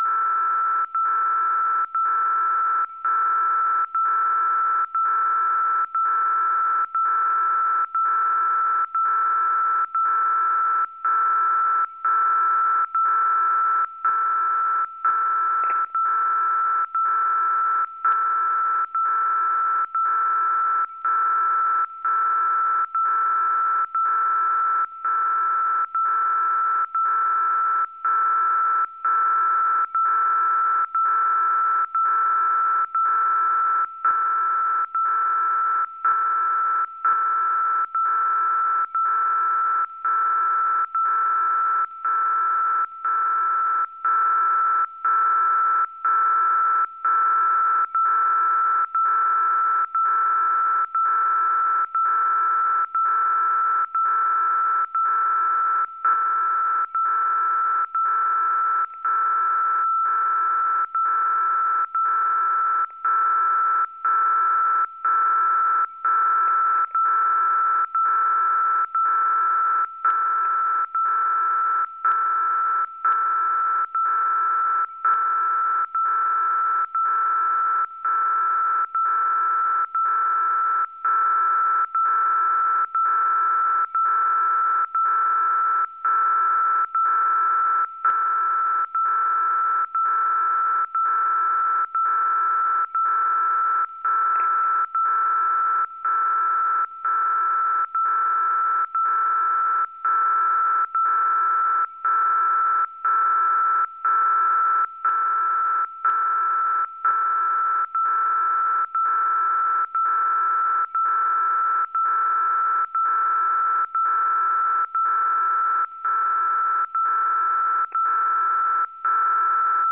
La prima operazione è quella di settare la frequenza al valore 77,5 kHz nel campo dedicato e selezionare la modalità LSB. Per  ricevere il segnale in maniera ottimale bisogna, in prima istanza, ascoltare in altoparlante il segnale, aumentare il volume ( andare oltre la metà della scala) e spostare la sintonia ( tramite i pulsanti + e -) in modo da udire un segnale audio come quello riproducibile da questo link.
audio DCF77.